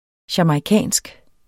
Udtale [ ɕamɑjˈkæˀnsg ]